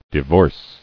[di·vorce]